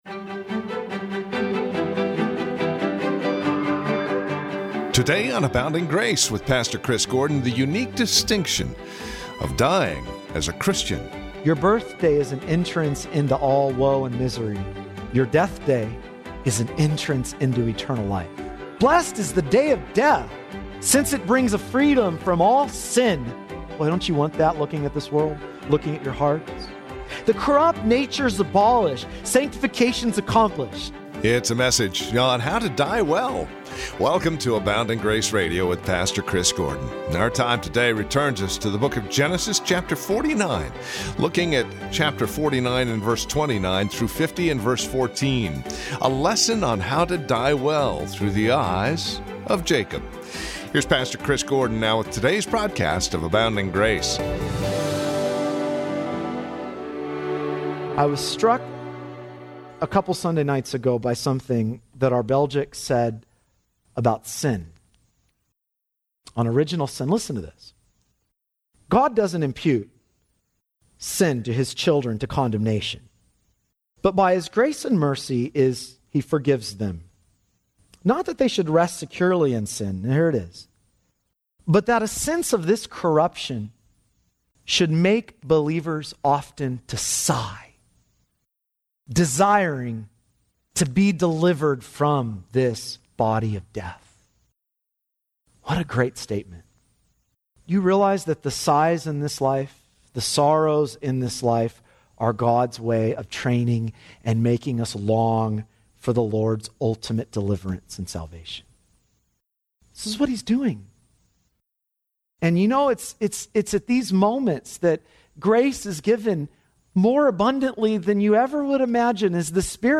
A Sermon On How To Die Well - Part 2
a_sermon_on_how_to_die_well_part_2_vemozpg9wy.mp3